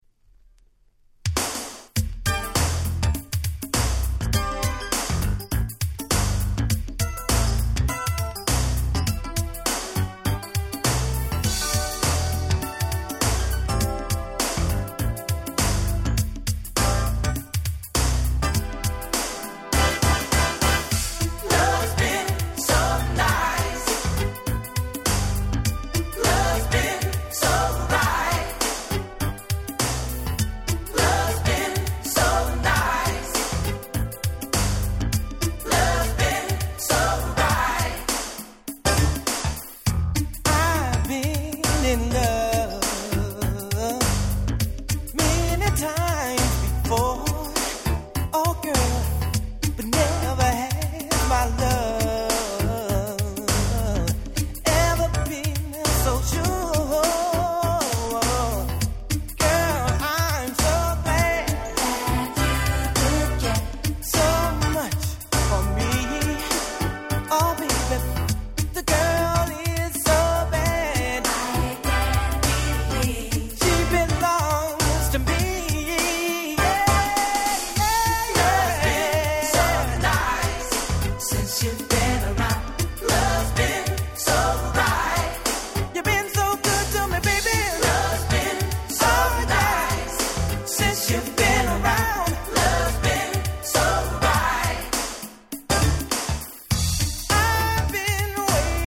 89' Big Hit R&B LP !!